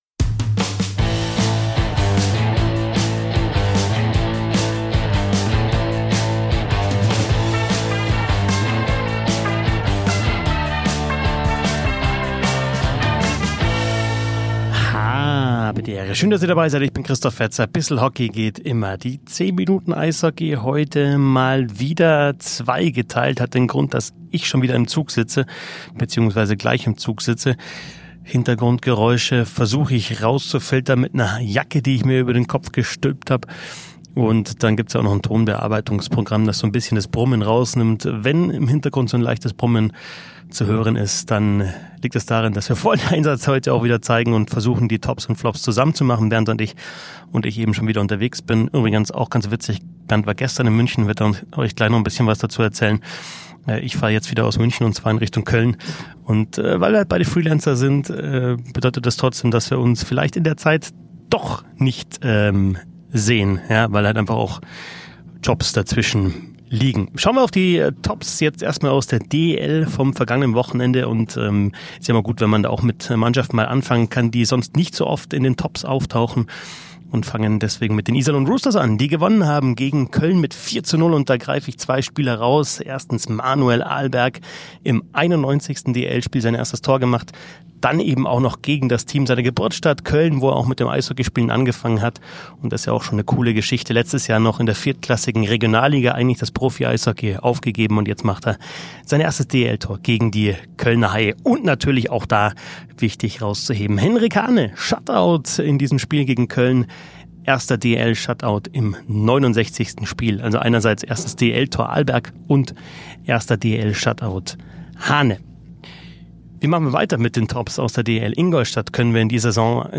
Der Eishockey-Podcast